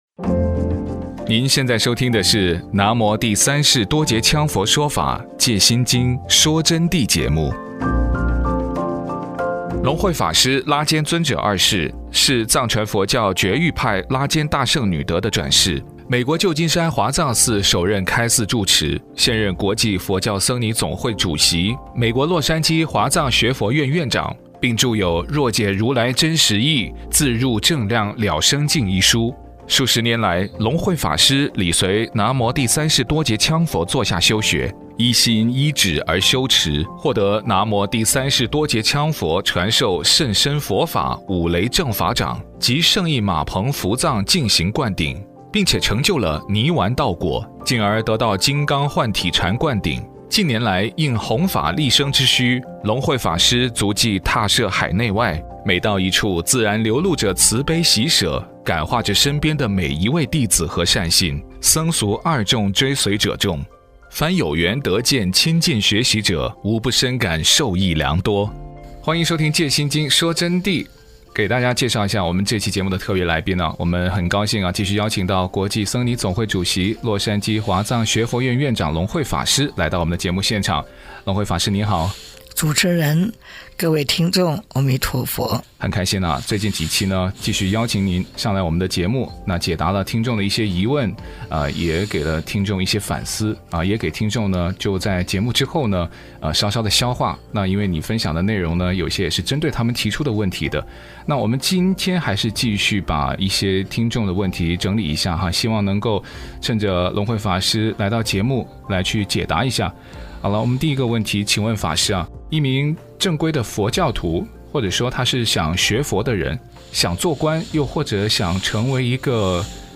佛弟子访谈（七十三）